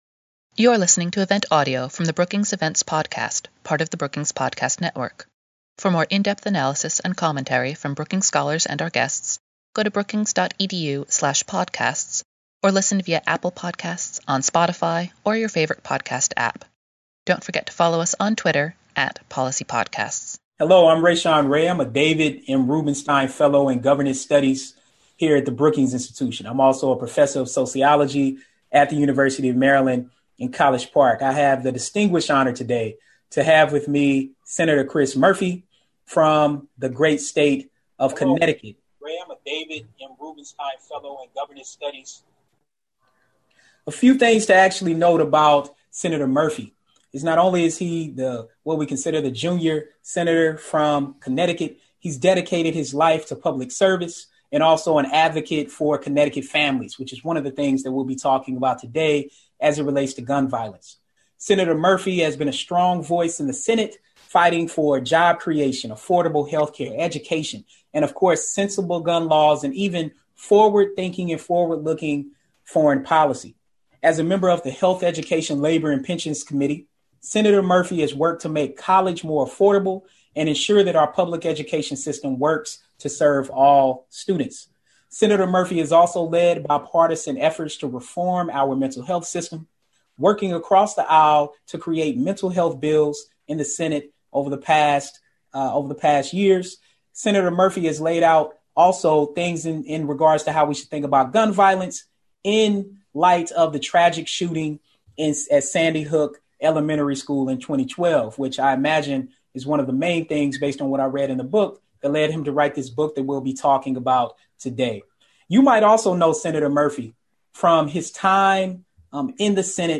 The pair discussed the history of violence in America and its long-term impacts, as well as the concrete steps that must be taken to change the nation’s narrative.